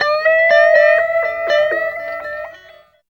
29 GUIT 4 -L.wav